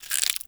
ALIEN_Insect_09_mono.wav